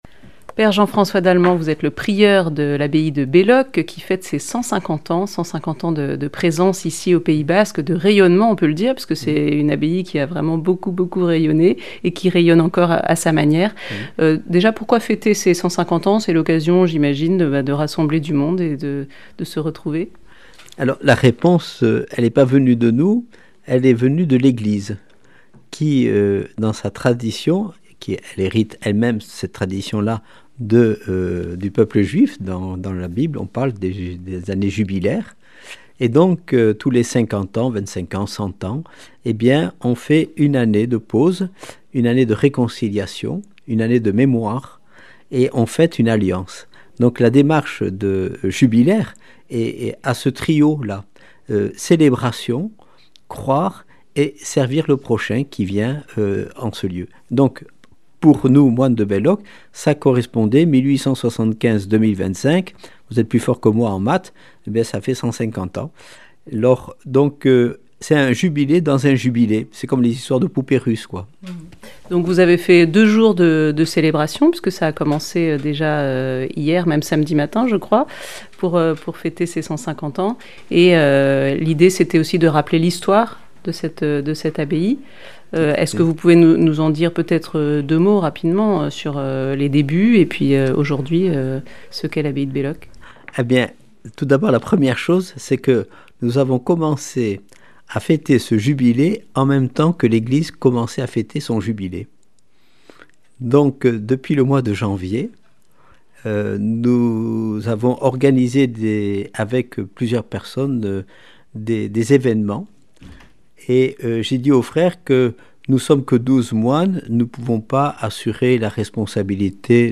Accueil \ Emissions \ Infos \ Interviews et reportages \ La communauté de Belloc fête 150 ans d’histoire.